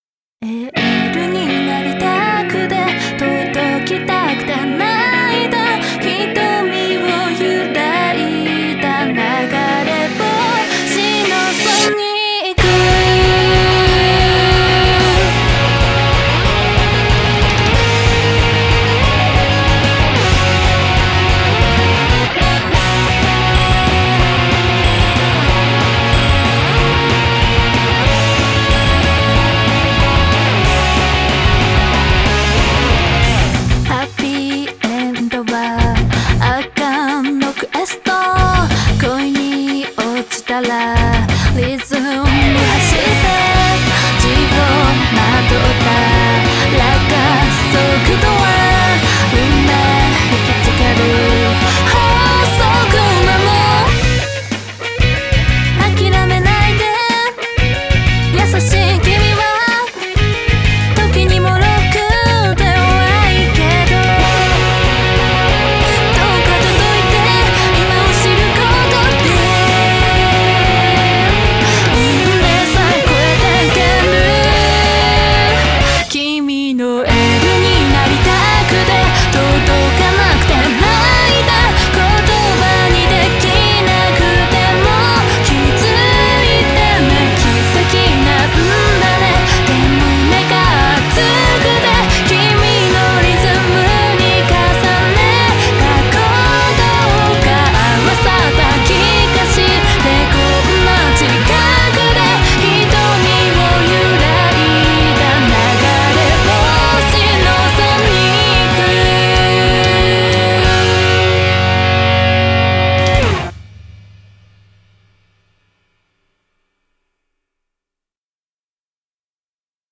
BPM172-186
Audio QualityPerfect (High Quality)
Genre: J-EMO.
You certainly will not notice the BPM change, hahaha.